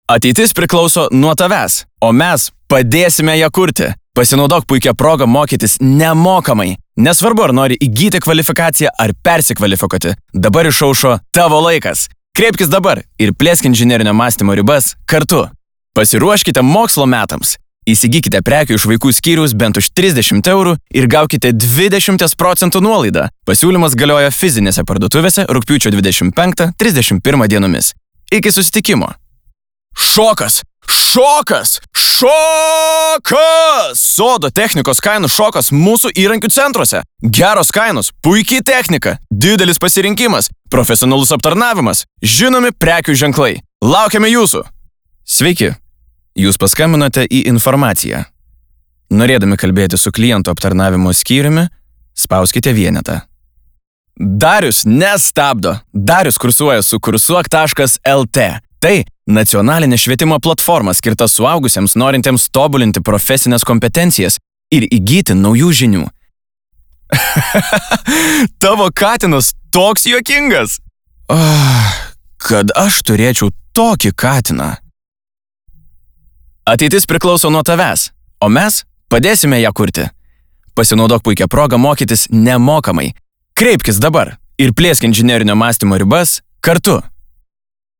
Diktoriai